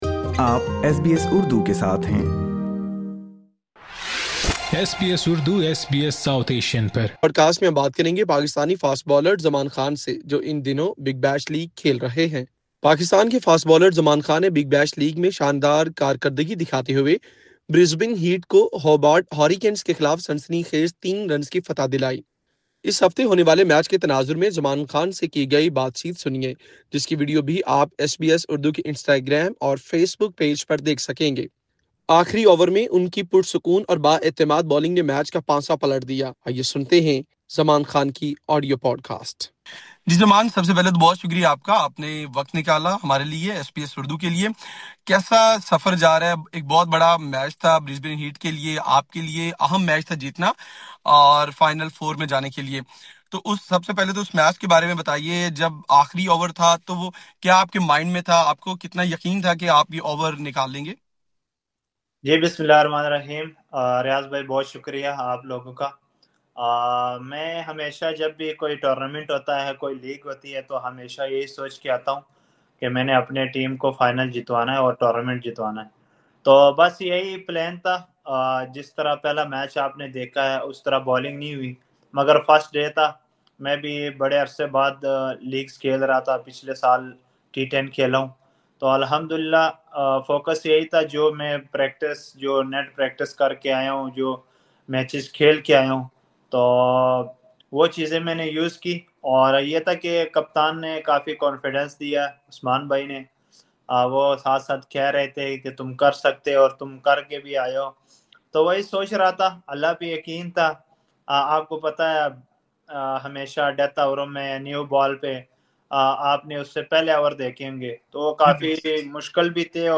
برسبین ہیٹ کو آخری اوور میں غیر متوقع جیت دلوانے والے باؤلر زماں خان کی زبانی سنئے برسبین ہیٹ کی غیر متوقع فتح کی کہانی اور اسُ آخری سنسنی خیز اوور کا احوال جس میں ہیریکین کو جیت کے لئے صرف چھ رنز درکار تھے مگر زماں خان نے آخری اوور میں نپی تُلی بالنگ کر کے اپنی ٹیم برسبین ہیٹ کو 3 رنز سے فتح فیصلہ کُن کردار ادا کیا۔